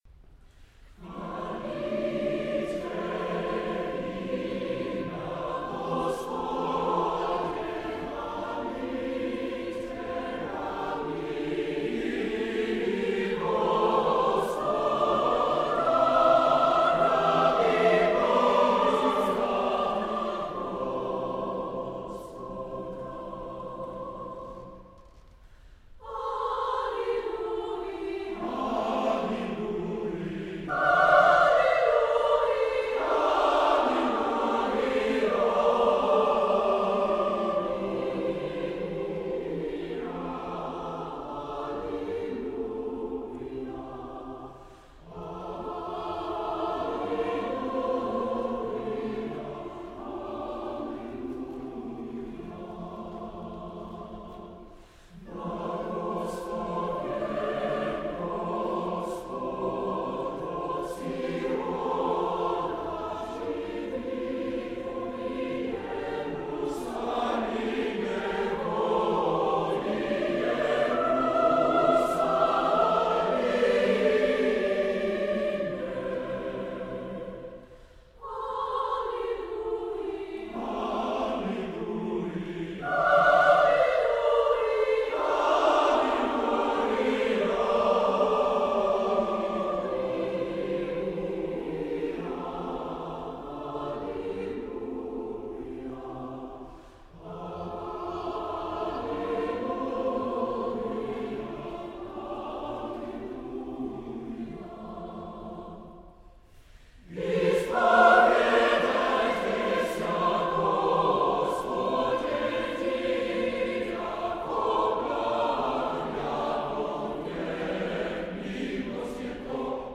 in Sanders Theatre, Harvard University, Cambridge, MA
Additionally, on May 13, 2011, the Chorale presented a performance rarity, the TCHAIKOVSKY All-Night Vigil, Op. 52 for unaccompanied chorus.